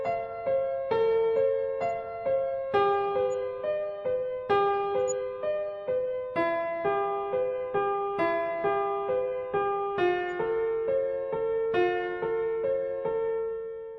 This sample was recorded using a sample rate of 24kHz and a bit-depth of 16 bits, so it's not great but serves as an example.
piano.mp3